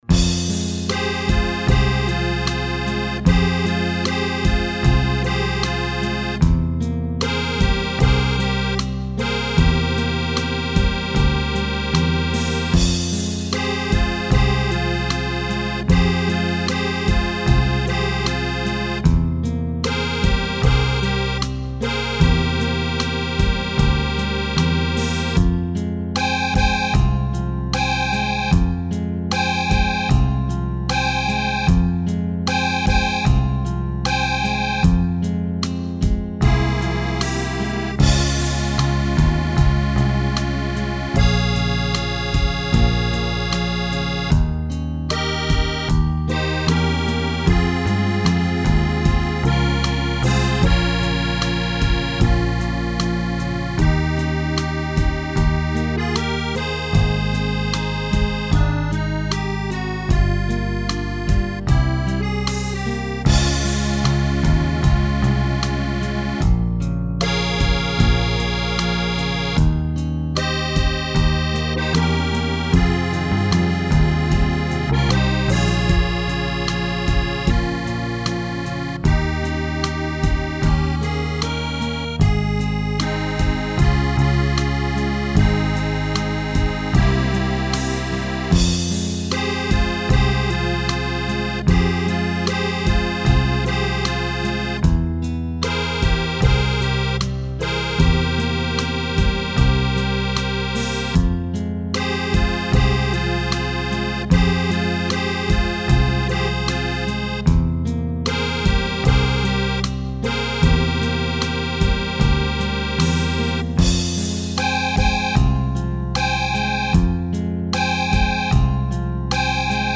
инструментальная пьеса